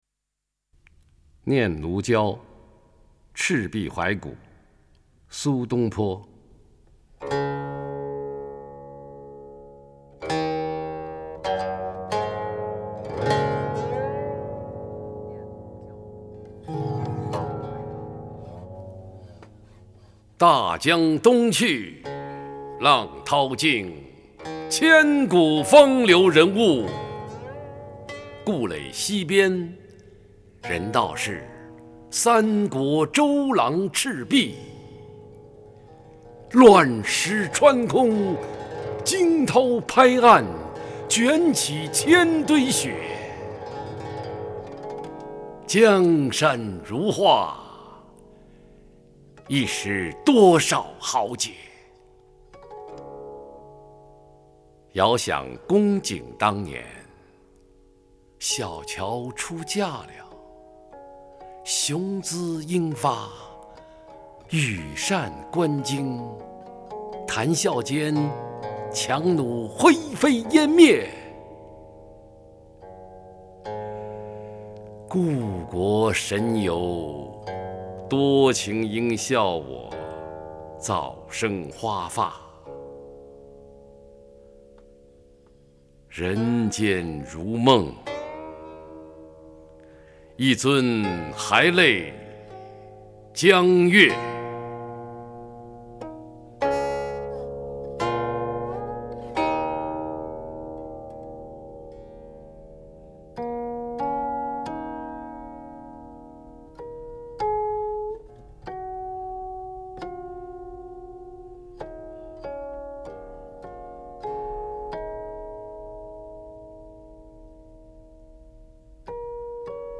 [5/5/2018]焦晃朗诵的苏轼名篇《念奴娇·赤壁怀古 》 激动社区，陪你一起慢慢变老！
朗诵：焦晃